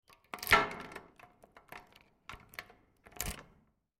Звуки калитки
Открываешь замок у калитки